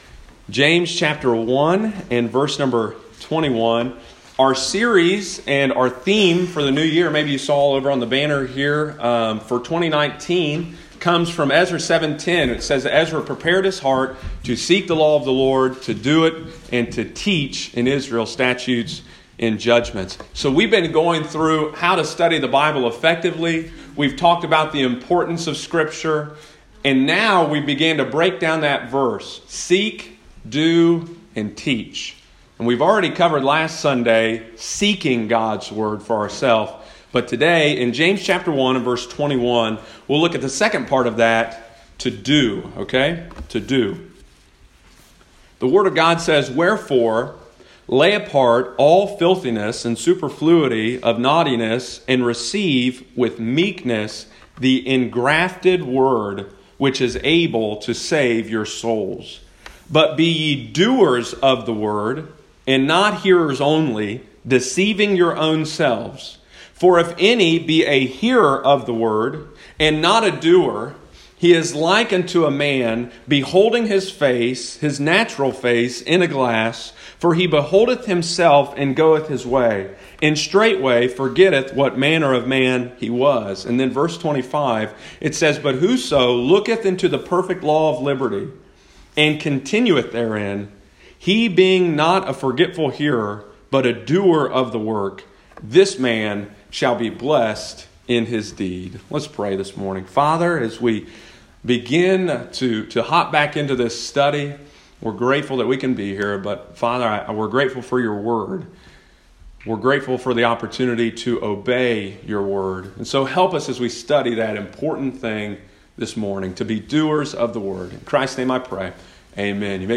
Sunday morning, January 28, 2019.